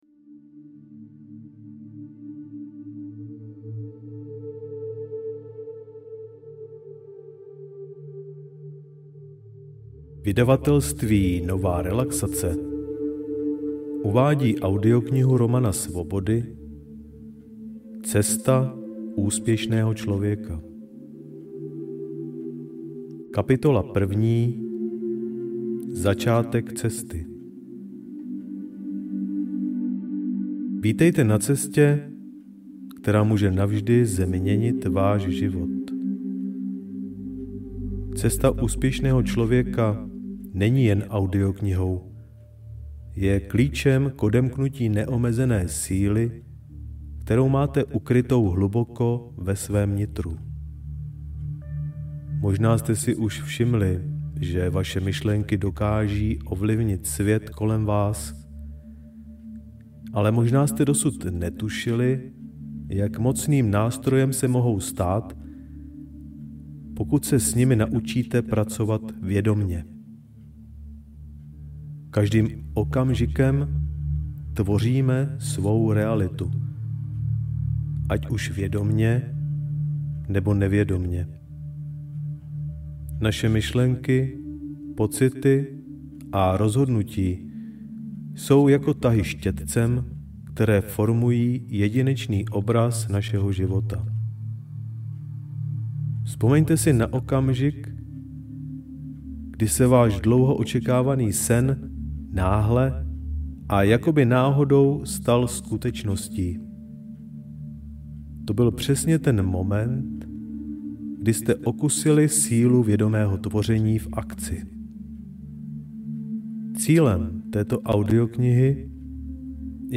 Cesta úspěšného člověka audiokniha
Ukázka z knihy